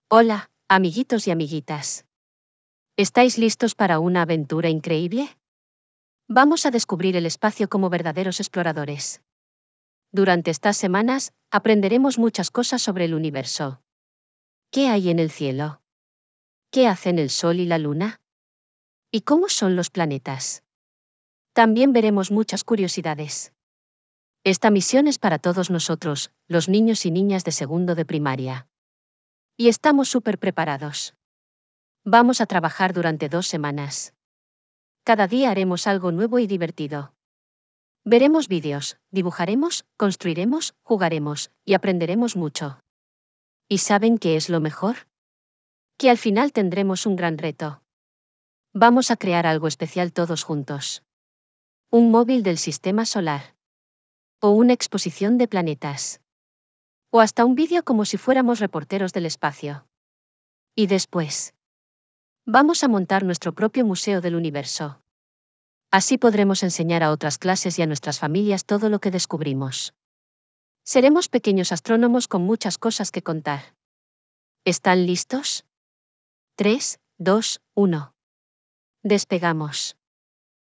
Lectura facilitada
Text_to_Speech.wav